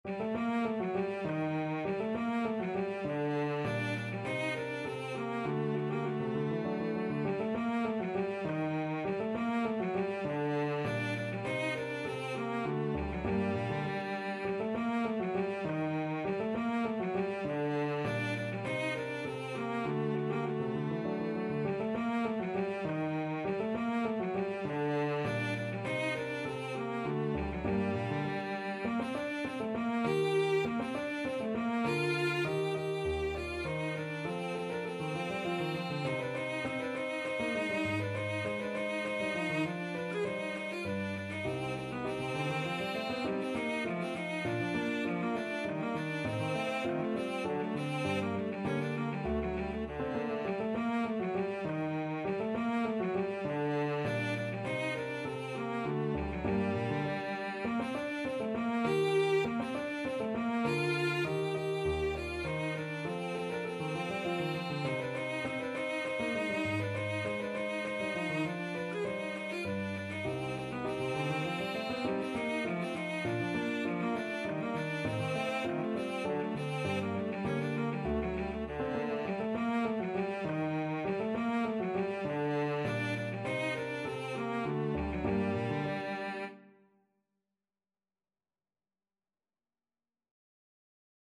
3/4 (View more 3/4 Music)
Allegretto = 100
D4-Ab5
Classical (View more Classical Cello Music)